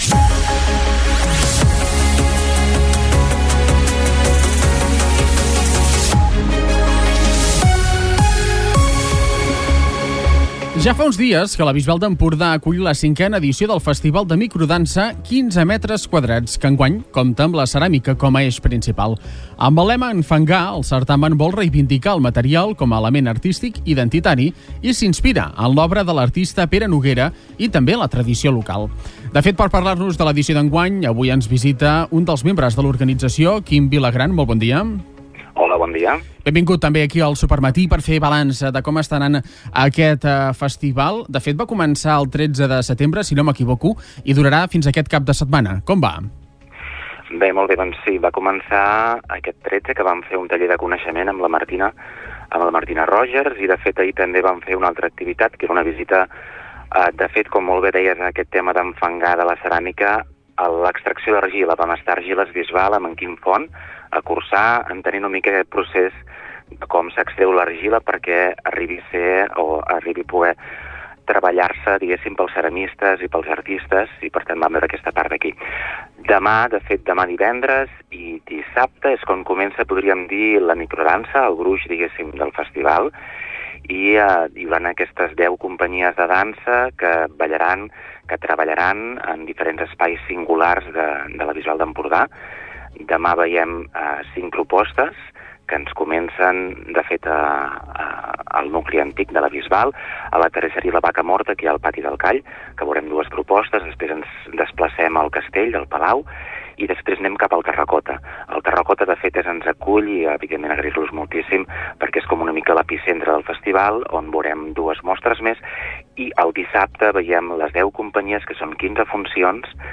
Torna a escoltar la inauguració de la XIII Fira d'Indians de Begur amb els parlaments d'Eugeni Pibernat, regidor de Promoció Econòmica; Mabel Arteaga, cònsol